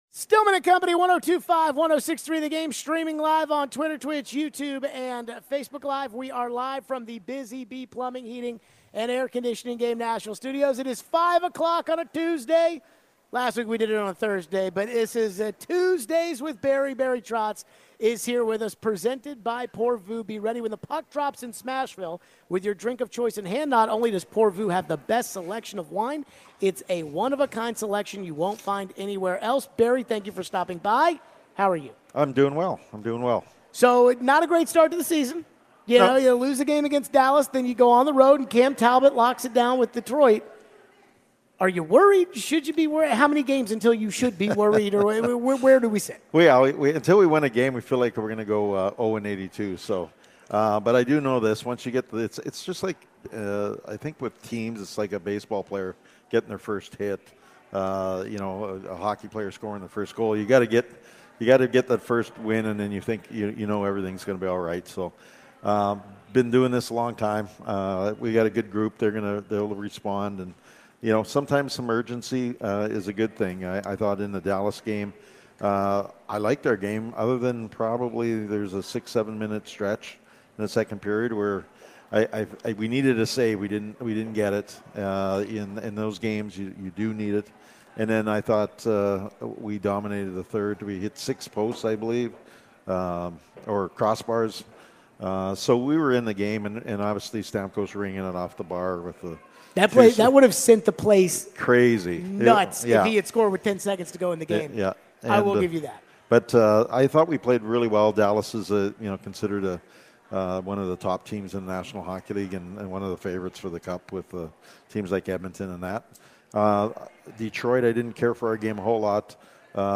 Hour 4: Barry Trotz interview: Levis' shoulder; Preds Gameday (10-15-24)
Preds GM Barry Trotz joins the show for his weekly visit talking hockey as the team hosts Seattle tonight. Will Levis talked about his shoulder and how it led to an INT vs Indy and what Callahan said about it. We wrap up the show with Preds Gameday.